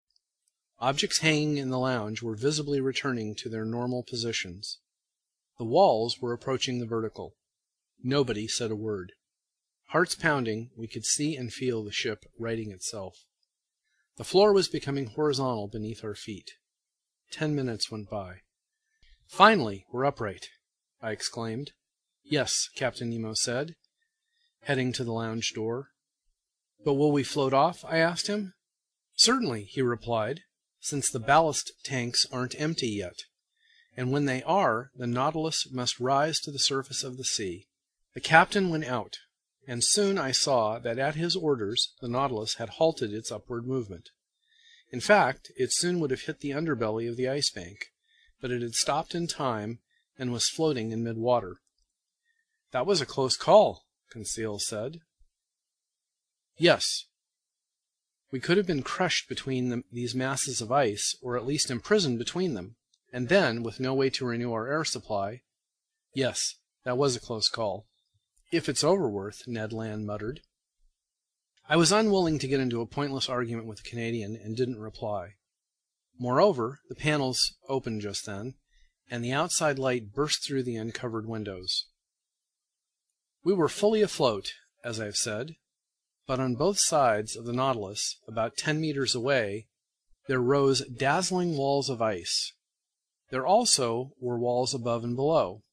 在线英语听力室英语听书《海底两万里》第464期 第28章 惊奇还是意外(6)的听力文件下载,《海底两万里》中英双语有声读物附MP3下载